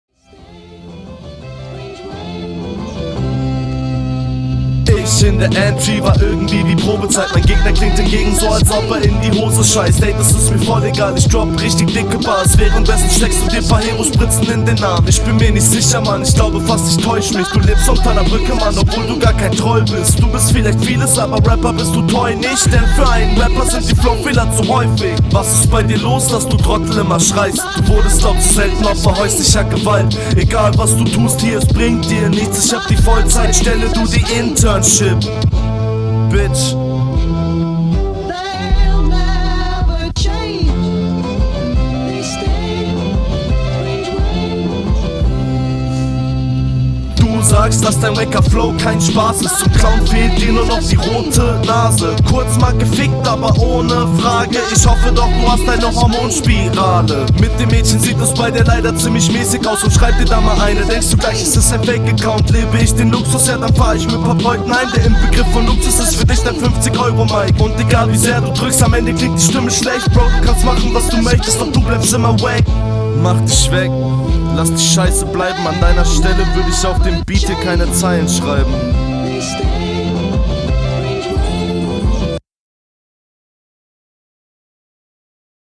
ok sorry, aber die erste sache die mir auffällt sind paar s laute und höhen …
Geiler Beat, rappst stabil drauf, Mische übersteuert n bissl und klingt n bissl nach zu …